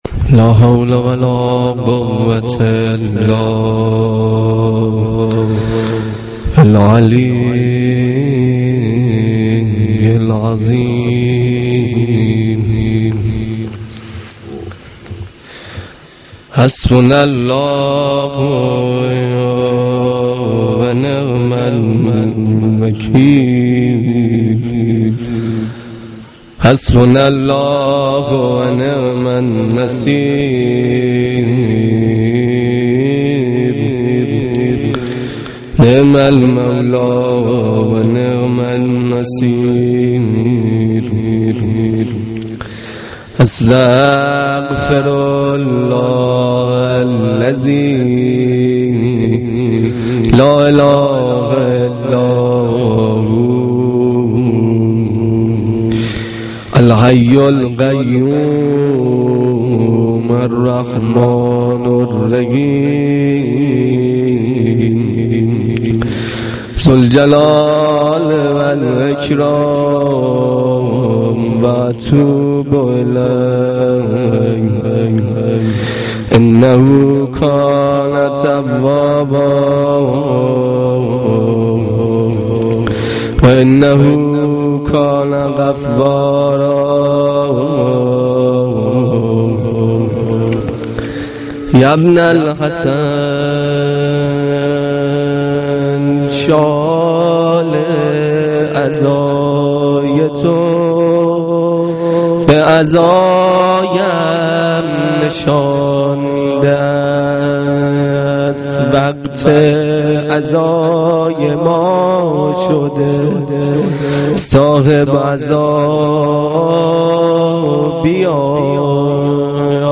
مداحی
Shab-8-Moharam-2.mp3